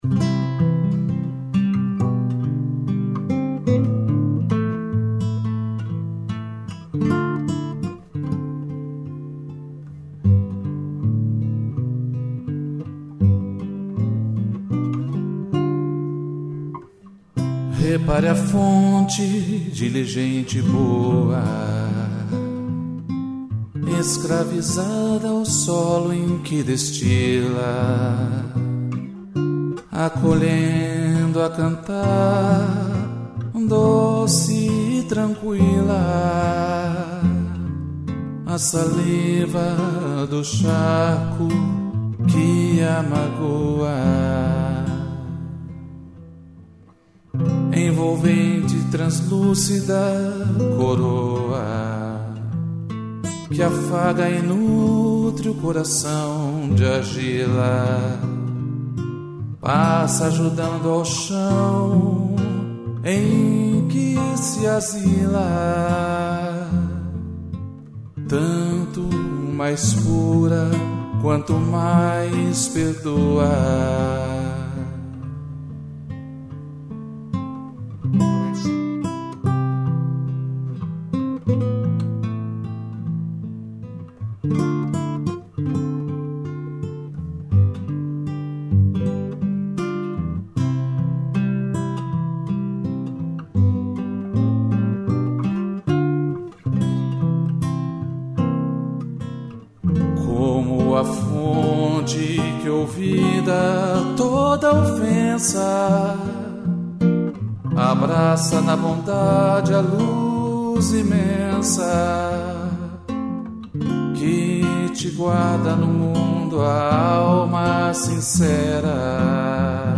violão e interpretação